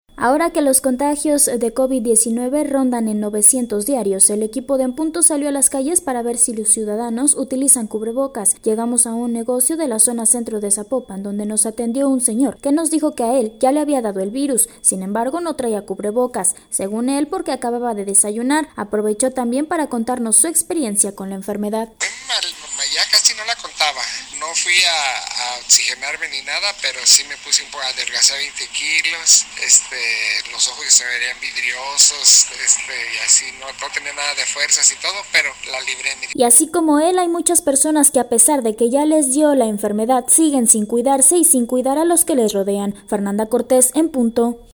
Ahora que los contagios de covid-19 rondan en 900 diarios, el equipo de En Punto salió a las calles para ver si los ciudadanos utilizan cubrebocas.